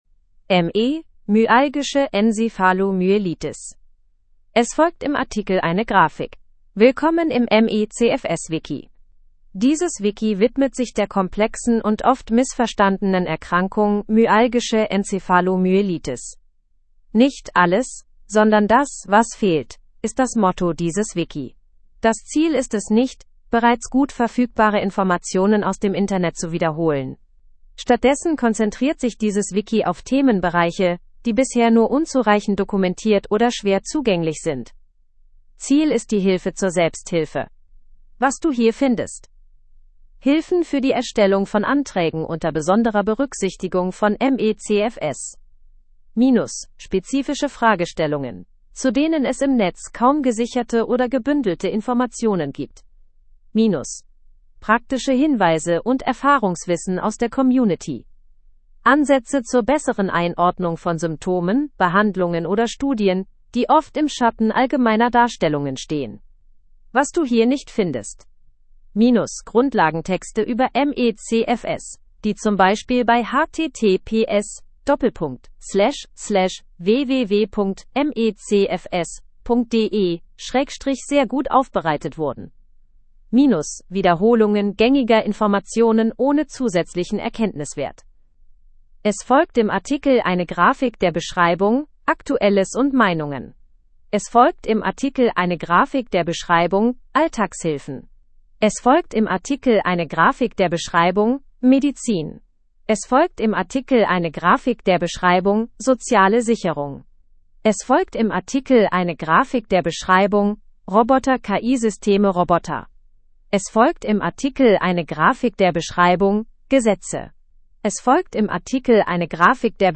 In Rahmen der Barrierefreundlichkeit bietet das Wiki folgende Möglichkeiten Die Artikel sind vertont und können sich somit angehört werden Für jeden Artikel gibt es eine Fassung in leichter Sprache.